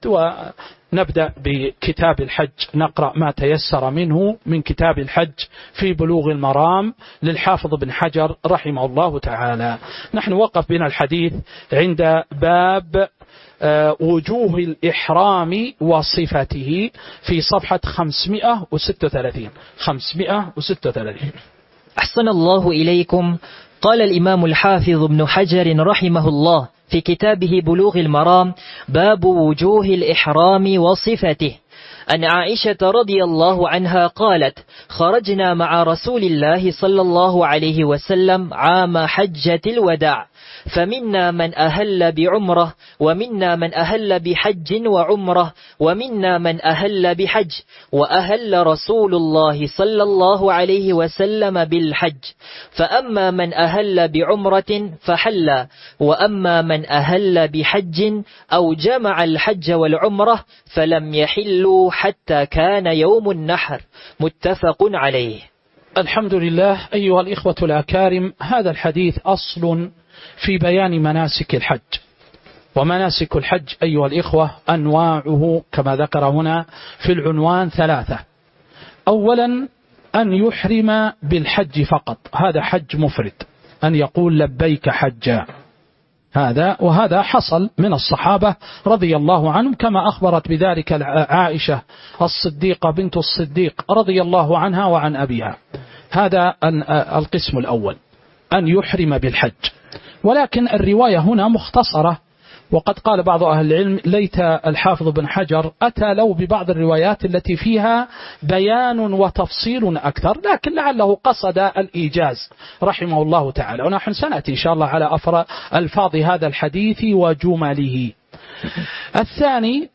تاريخ النشر ١٠ ذو القعدة ١٤٤٥ هـ المكان: المسجد النبوي الشيخ